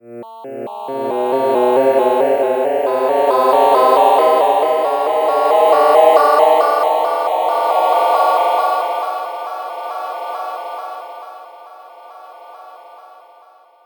2025 7/4 サーというノイズを取り除いて音をクリアにしました
場面転換21/乾いた終
scene_change21.mp3